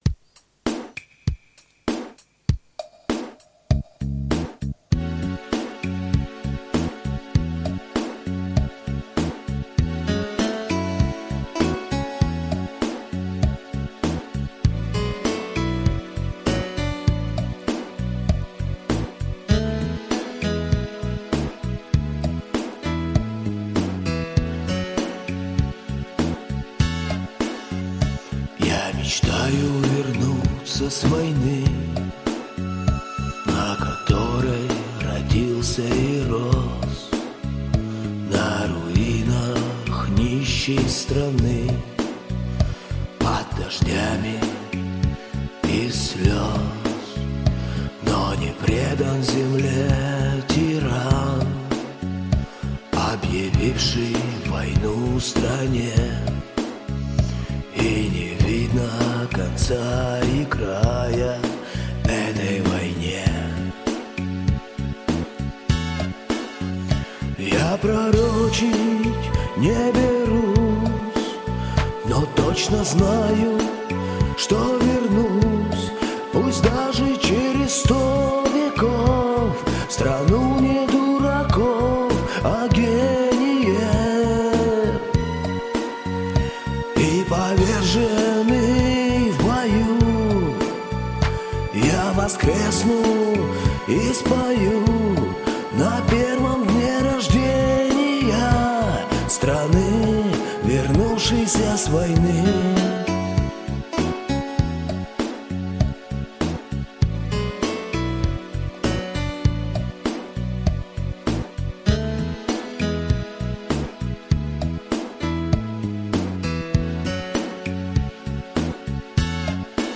Но исполнитель слева спел "спокойней" и убедительней...